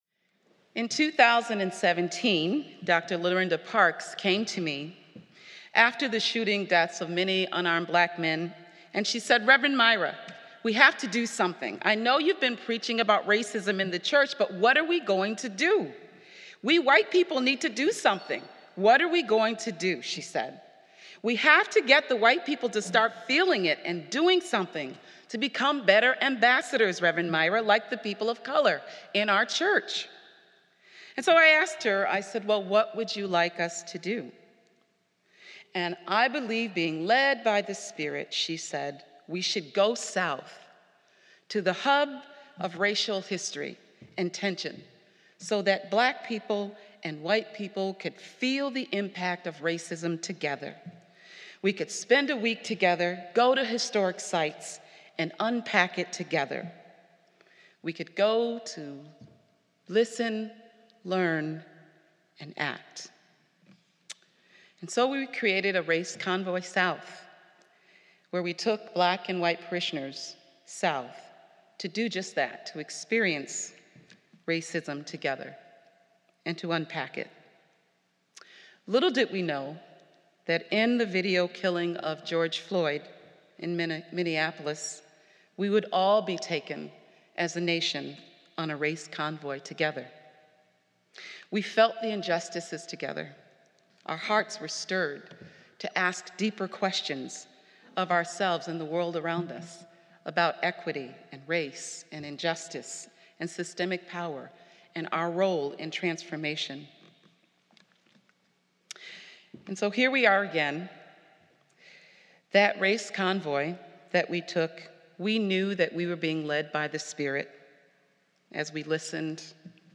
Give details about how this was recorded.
We celebrate the Feast of Pentecost